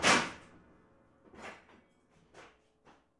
房子周围 " 锤子
描述：这是一个基本的锤子打在钉子上被打入木头的过程。它是用我的Walkman Mp3播放器/录音机录制的，并进行了数字增强。
标签： 钉子
声道立体声